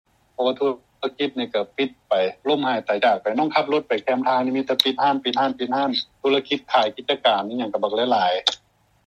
ສຽງ 1 ພະນັກງານໃນອົງການສາກົນທ່ານນຶ່ງ ເວົ້າກ່ຽວກັບຜົນກະທົບຂອງໂຄວິດ-19